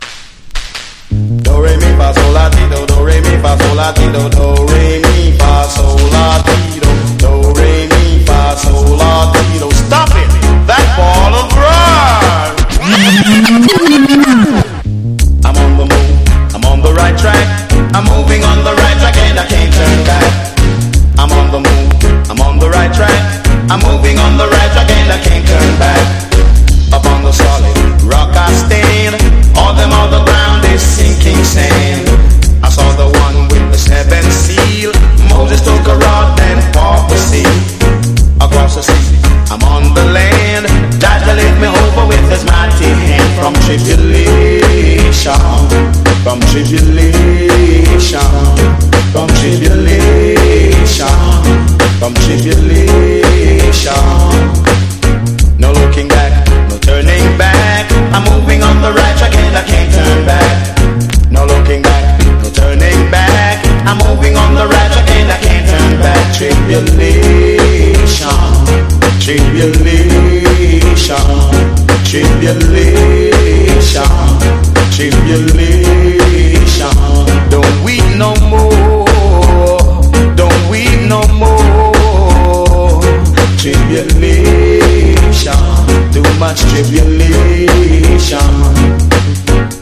(ジャマイカ盤特有のチリノイズ入ります)